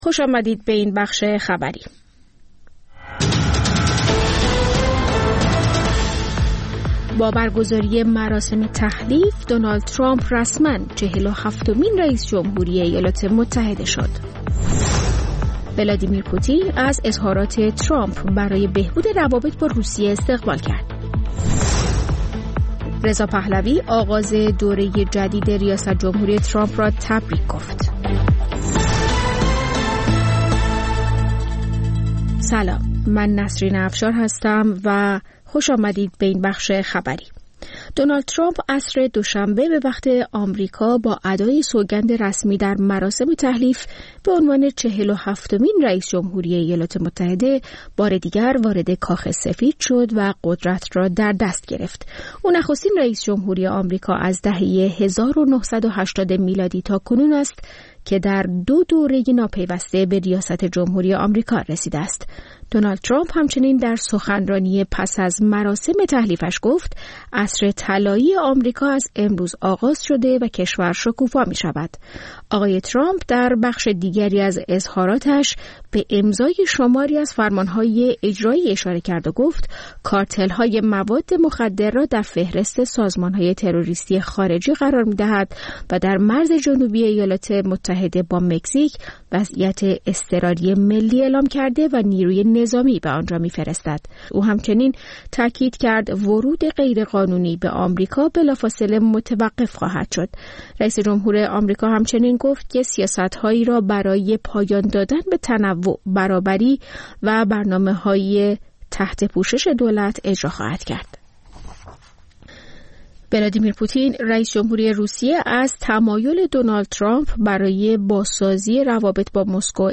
سرخط خبرها ۷:۰۰